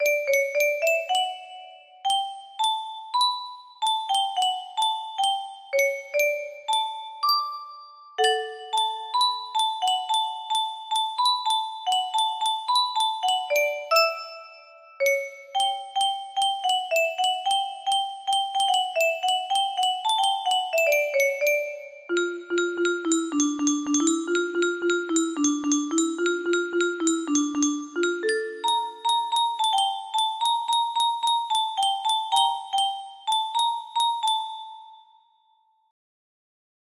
Baby Goat music box melody